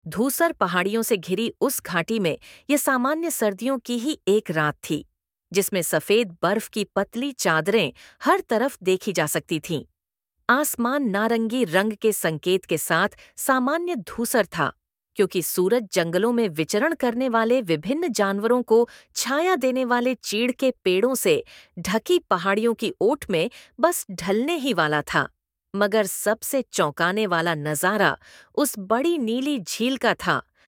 PLAY VOICE SAMPLE